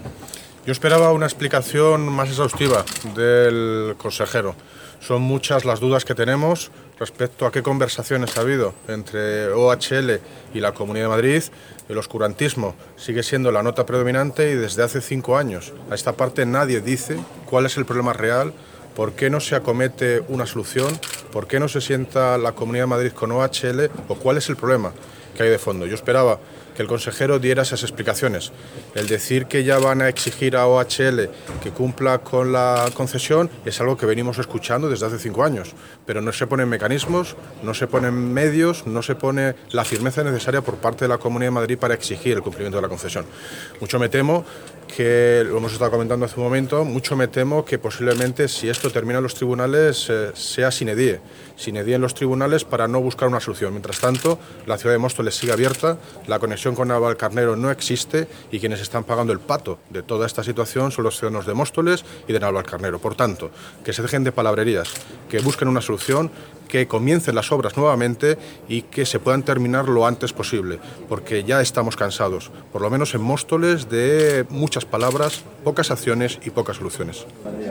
Audio - David Lucas (Alcalde de Móstoles) Sobre Tren Mostoles Navalcarnero